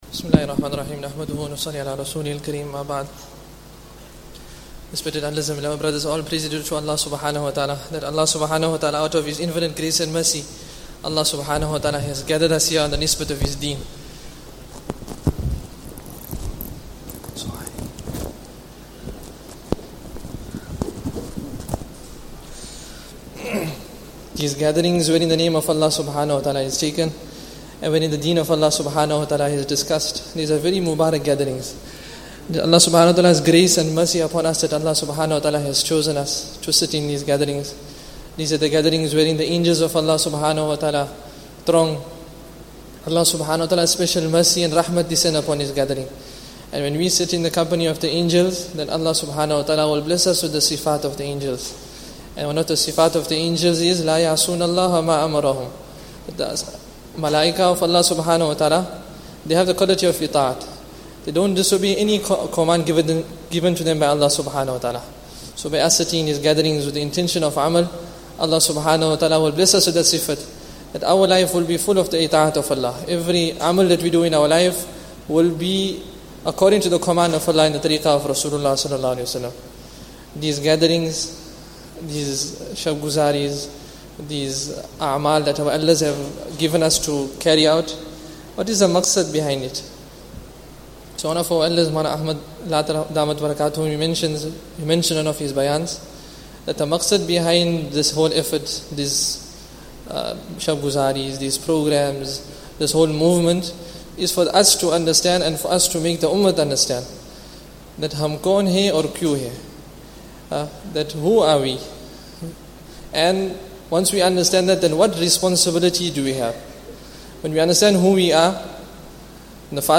After Asr Bayaan